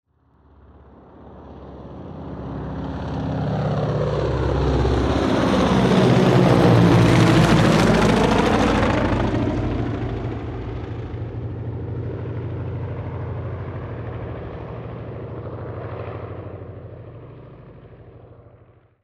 دانلود آهنگ هلیکوپتر 6 از افکت صوتی حمل و نقل
دانلود صدای هلیکوپتر 6 از ساعد نیوز با لینک مستقیم و کیفیت بالا
جلوه های صوتی